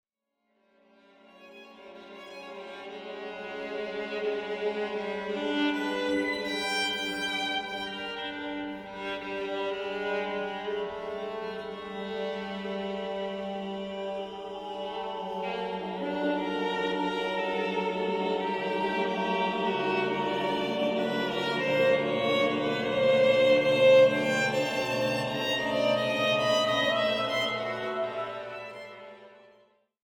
für gemischter Chor und Violine
Neue Musik
Vokalmusik
Gemischter Chor
gemischter Chor (1, SSAATTBB), Violine (1)
Juli 2023 - Stiftskirche Ossiach